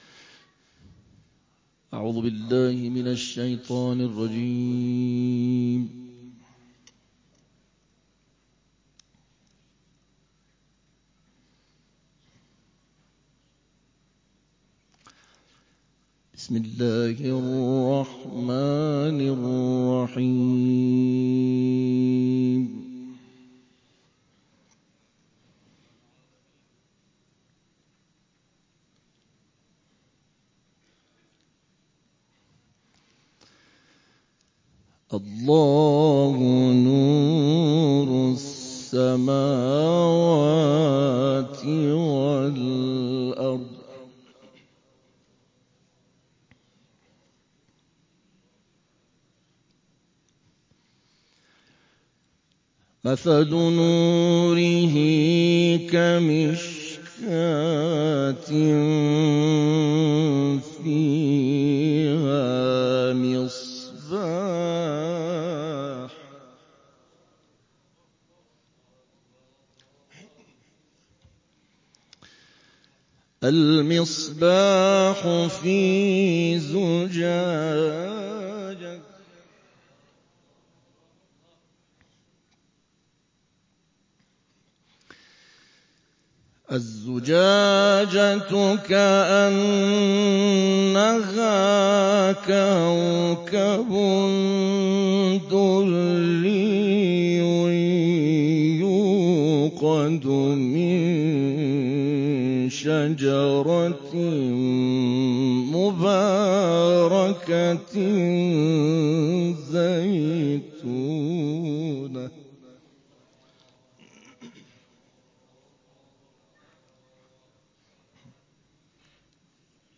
مراسم غبارروبی و محفل انس با قرآن و عترت با حضور اعضای جامعه قرآنی شامگاه گذشته و در آستان مطهر حضرت صالح بن موسی کاظم(ع) در محله تجریش تهران برگزار شد.